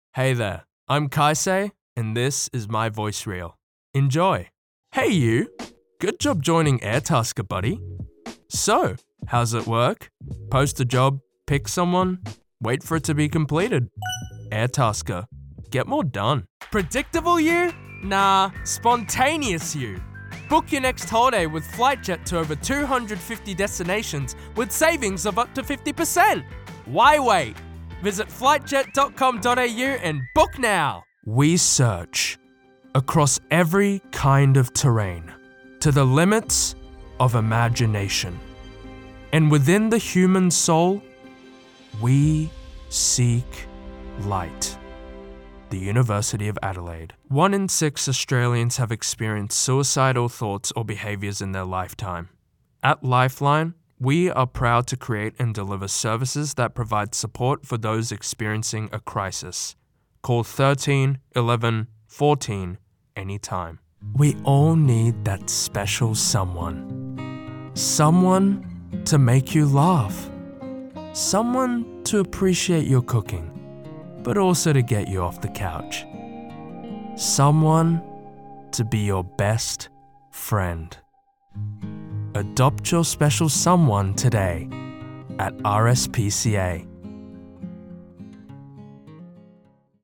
Hire Professional Male Voice Over Talent, Actors & Artists Online
I'm a French European voice talentsince many years.
Adult (30-50) | Older Sound (50+) I'm a French European voice talentsince many years.